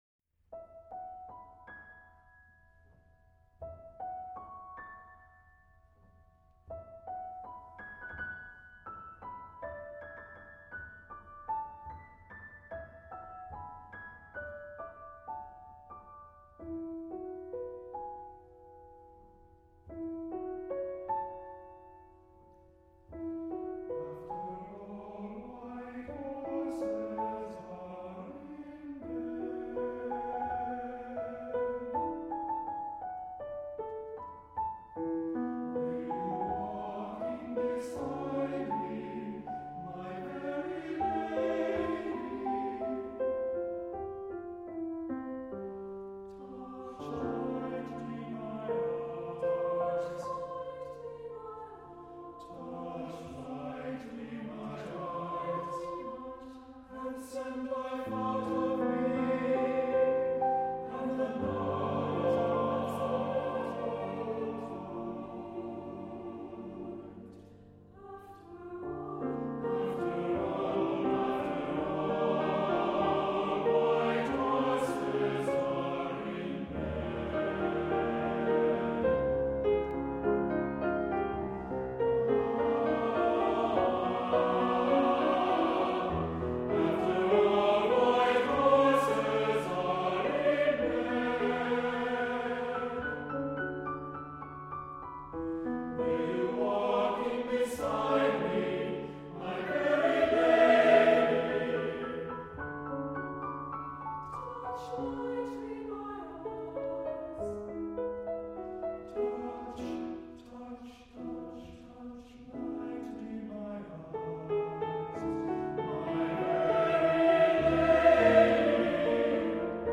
for SATB Chorus and Piano (1979)